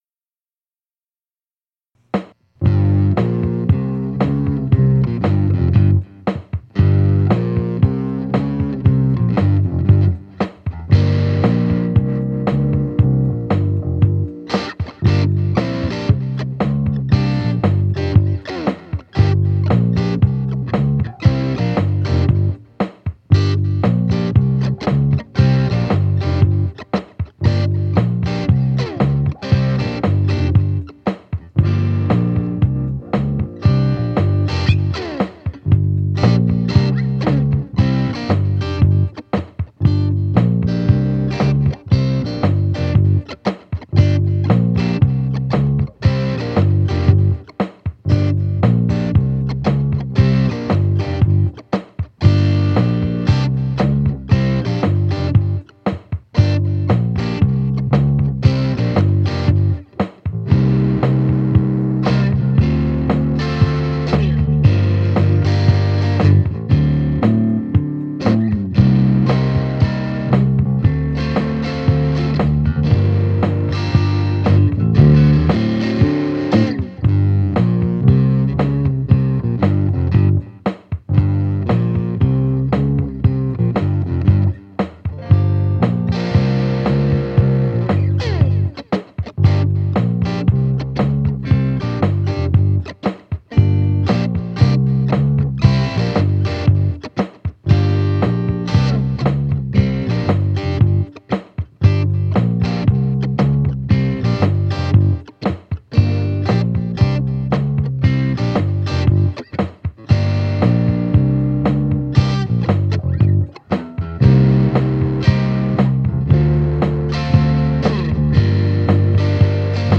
kinda in the blues/rock genre
jam track